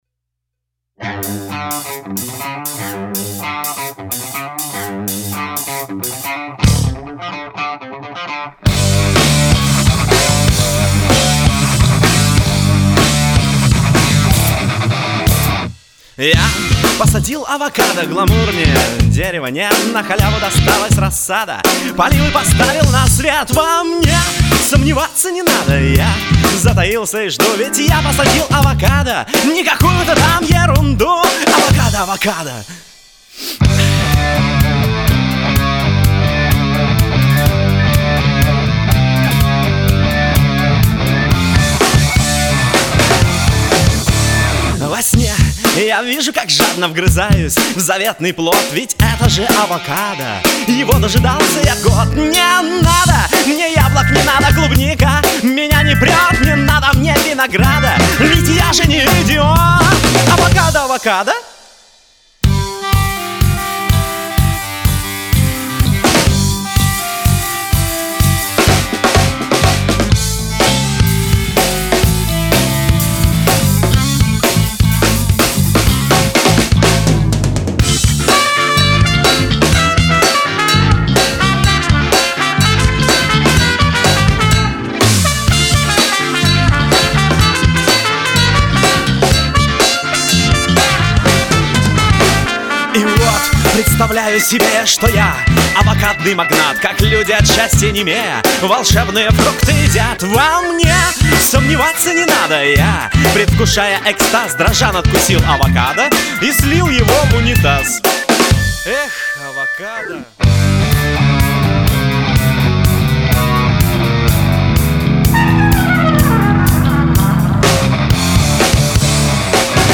О треке: все инструменты (включая барабаны) - живые.